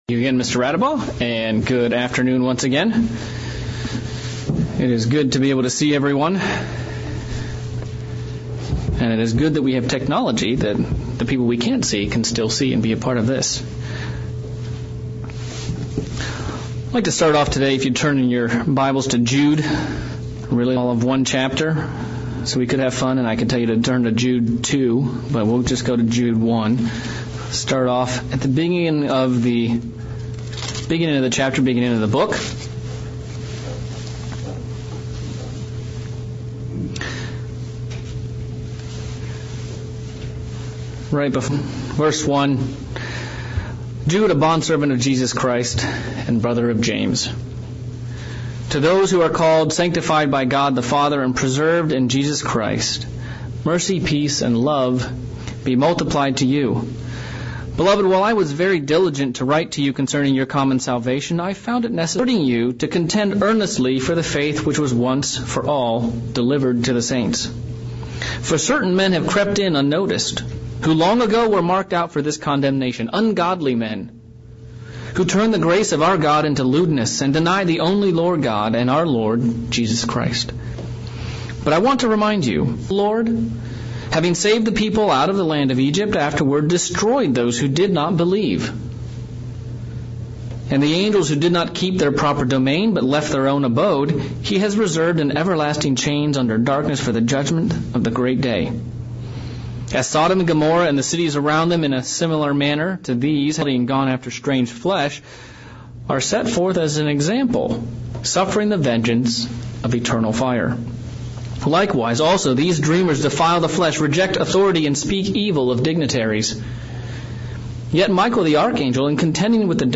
3 part sermon series looking at the 3 warnings found in Jude :11. #1 - What is the way of Cain and how can we avoid it in our lives?